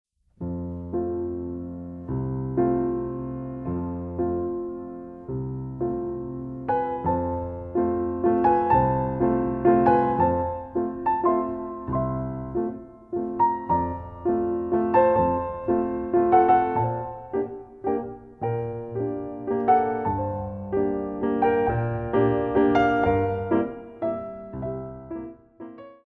Music for Ballet Class
Ronds de jambe
8x8 - 3/4